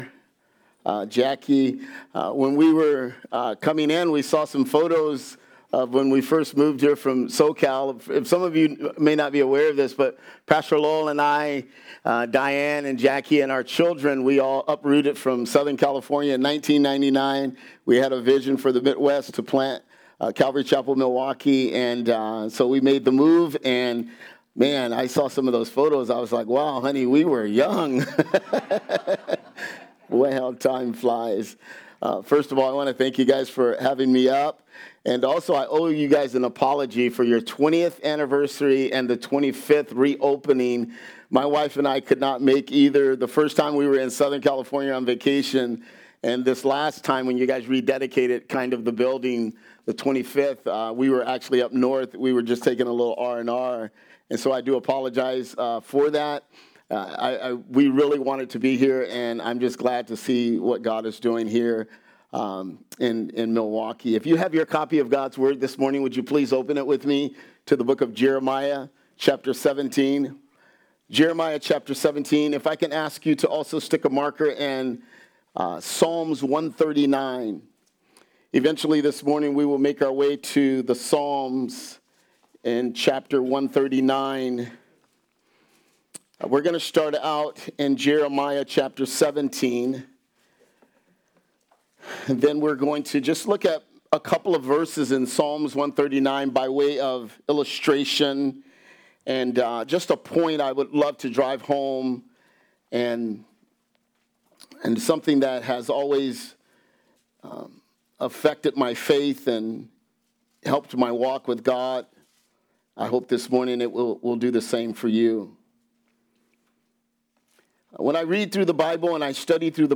Sermons | Calvary Chapel Milwaukee
for our Sunday service.